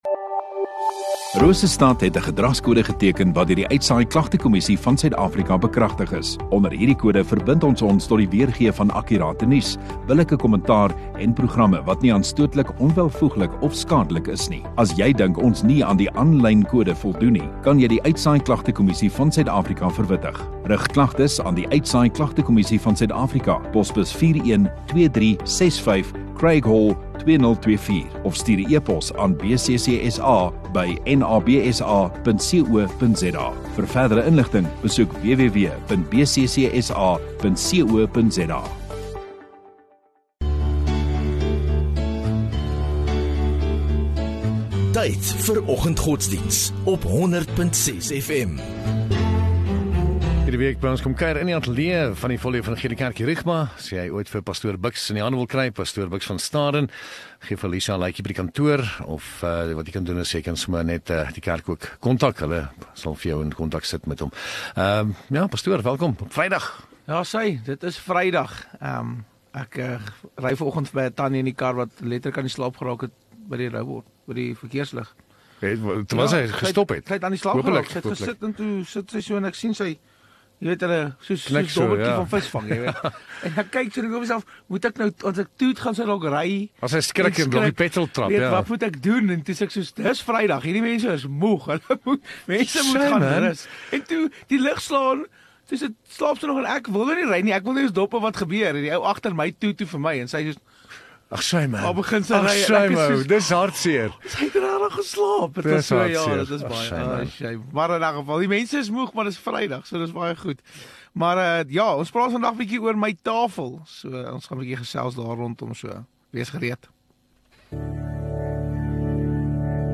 31 May Vrydag Oggenddiens